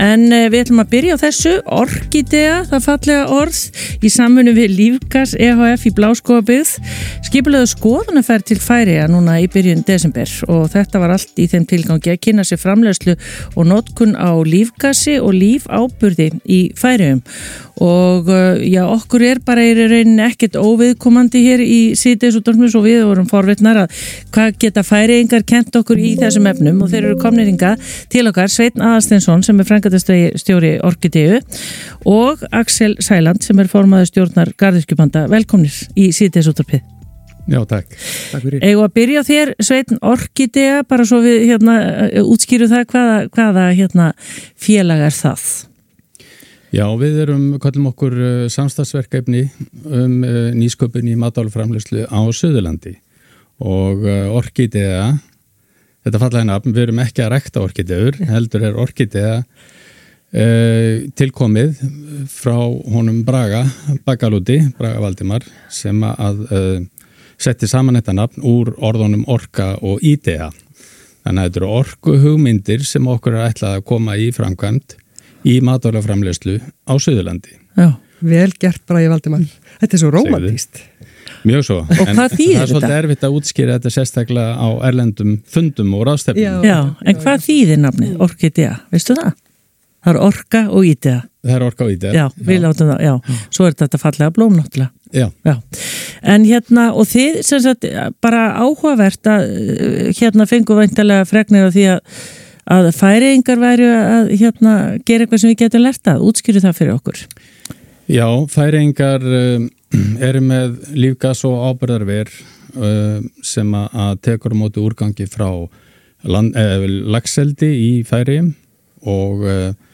Upptöku af viðtalinu má hlusta á hér